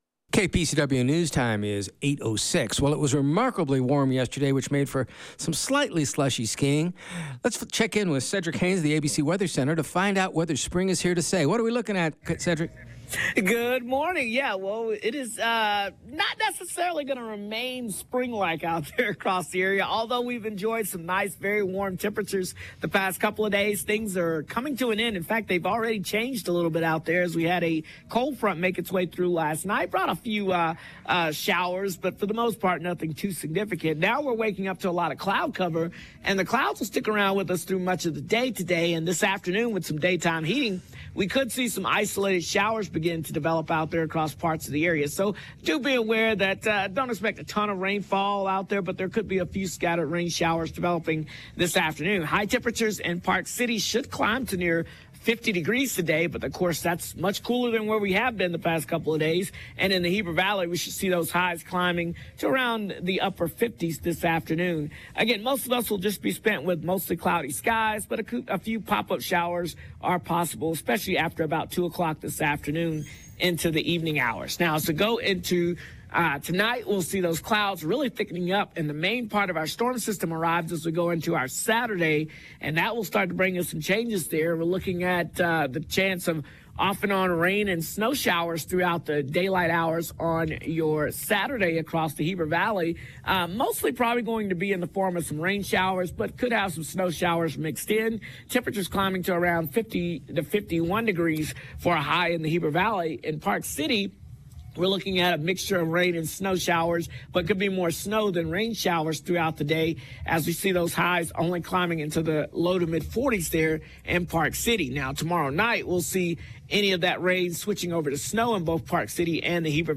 Get the information directly from the people making news in the Wasatch Back with live interviews every weekday.